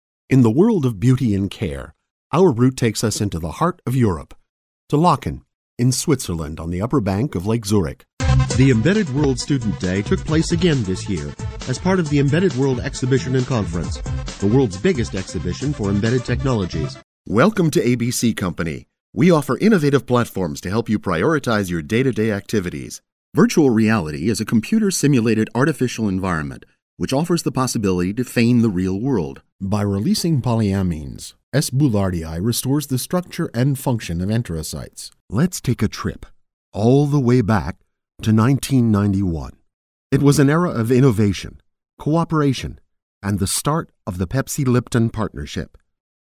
Voice of reason, warm, real, clear friendly, articulate, clear, versatile, and confident. Paternal, warm, conversational, versatile, and confident... and professional. Home studio & phone patch for direction via Skype.
mid-atlantic
middle west
Sprechprobe: Industrie (Muttersprache):